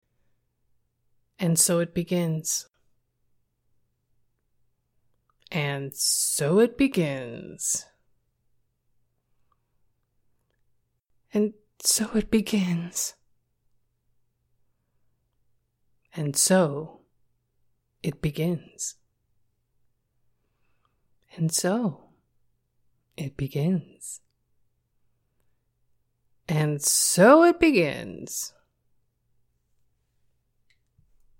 Download Female sound effect for free.